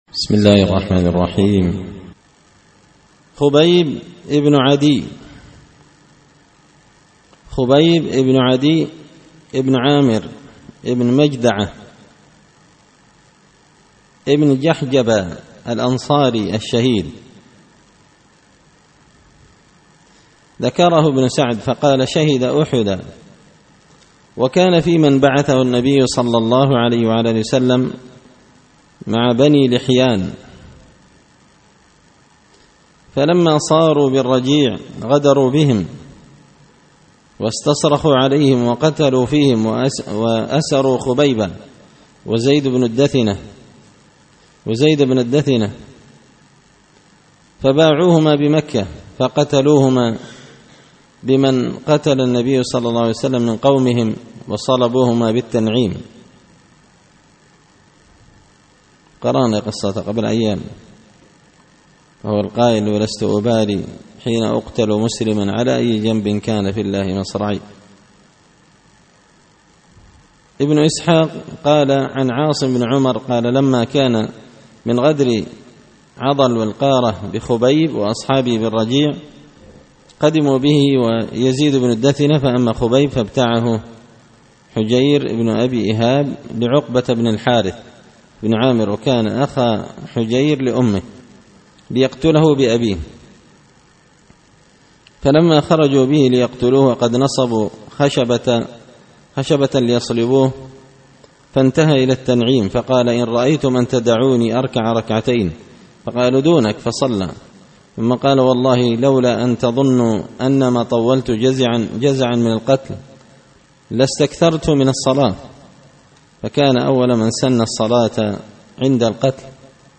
قراءة تراجم من تهذيب سير أعلام النبلاء
دار الحديث بمسجد الفرقان ـ قشن ـ المهرة ـ اليمن